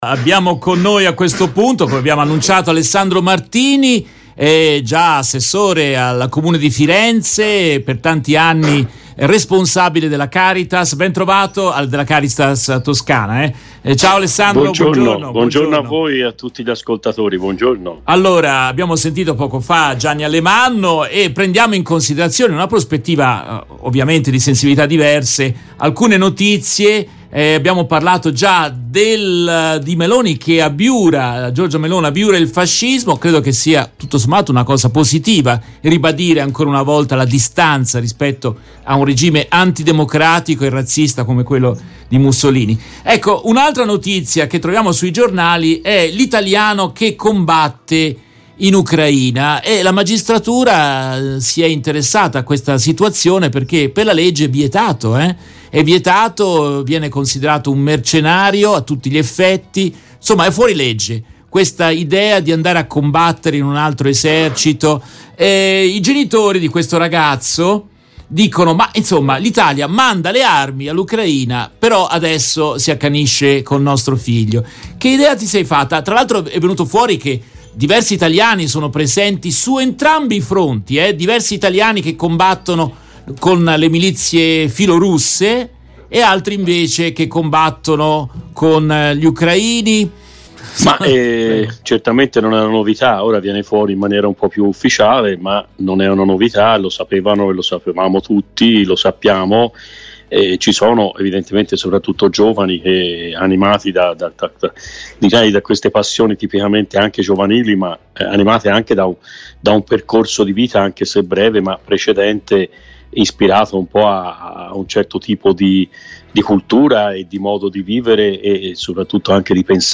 In questa intervista tratta dalla diretta RVS del 11 agosto 2022, ascoltiamo Alessandro Martini, già assessore al Comune di Firenze e direttore della Caritas della Toscana.